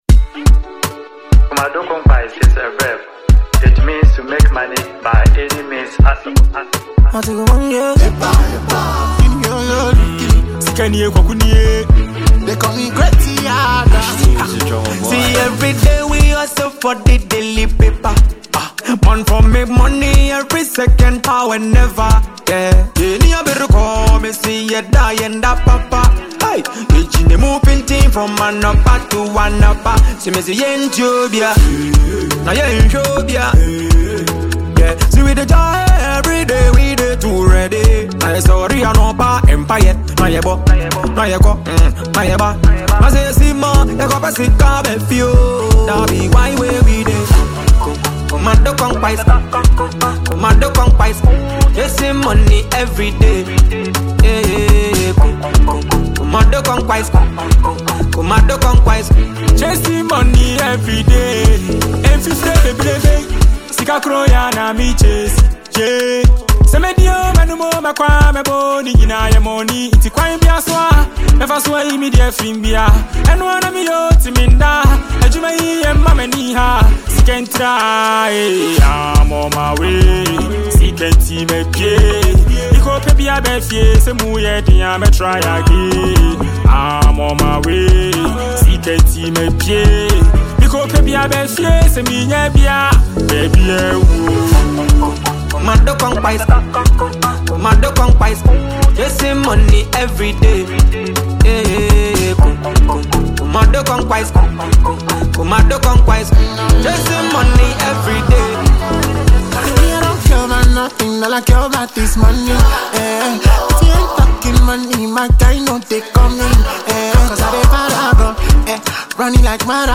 Ghanaian singer
Afrobeats